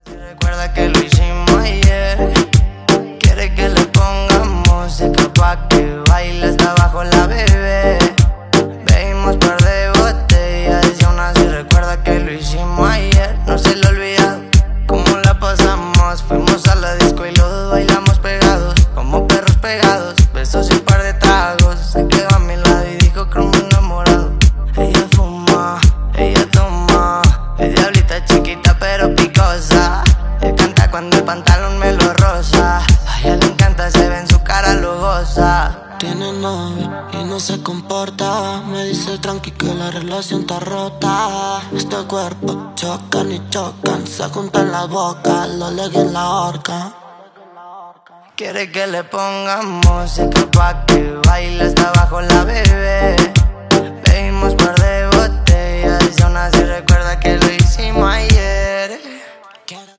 Unleash the Power of Sundown Audio Subwoofers!